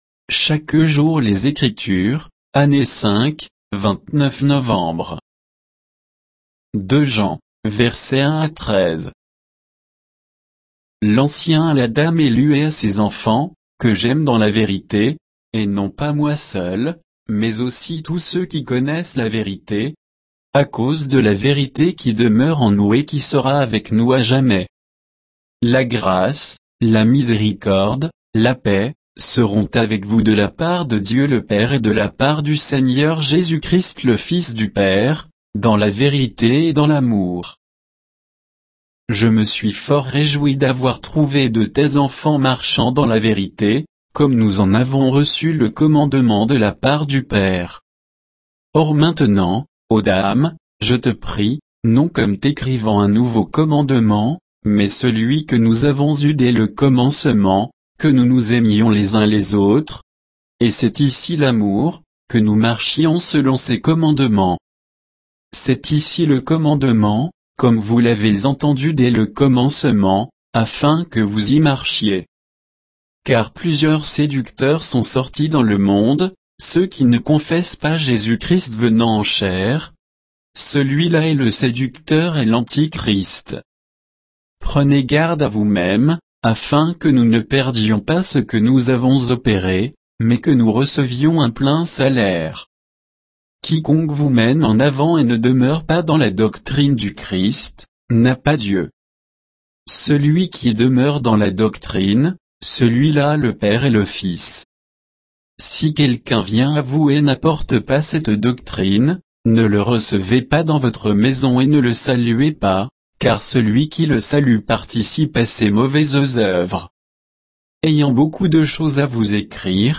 Méditation quoditienne de Chaque jour les Écritures sur 2 Jean 1 à 13